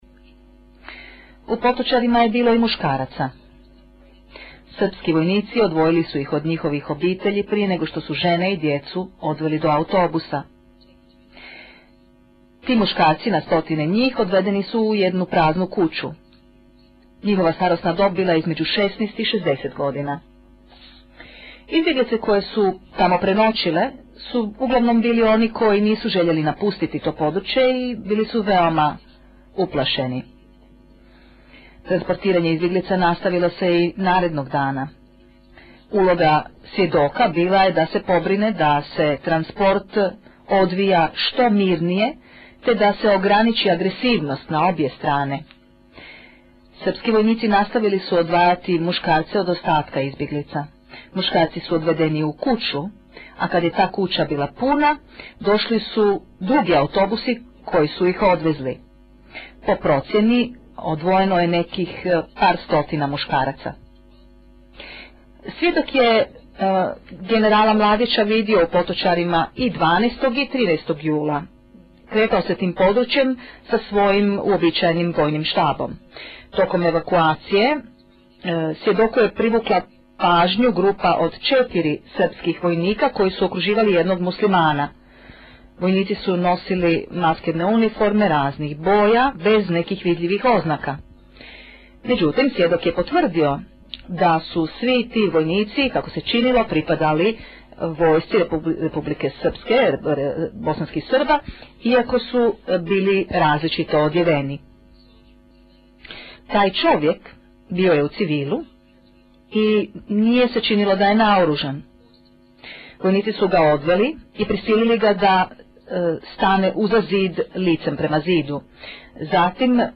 Tužiteljica čita iskaz o odvajanju muškaraca i ubojstvu